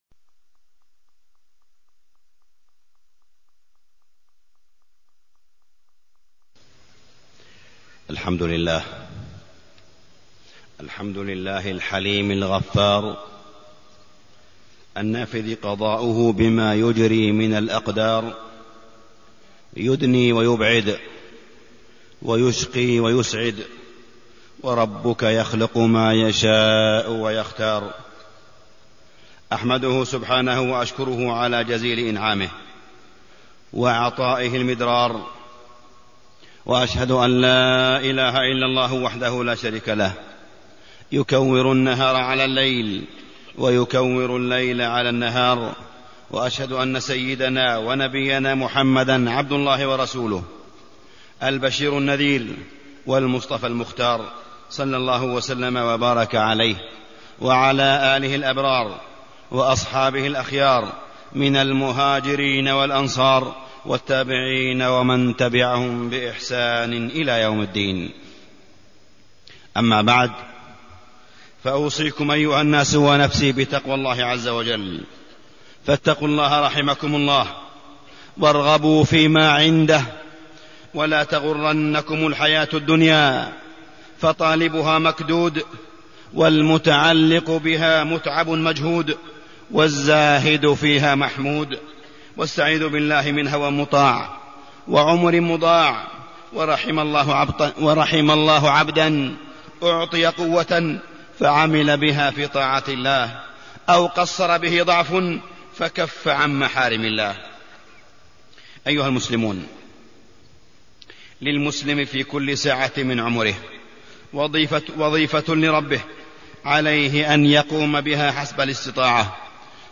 تاريخ النشر ٢٥ ذو القعدة ١٤٢٢ هـ المكان: المسجد الحرام الشيخ: معالي الشيخ أ.د. صالح بن عبدالله بن حميد معالي الشيخ أ.د. صالح بن عبدالله بن حميد العمل الصالح بيانه وقبوله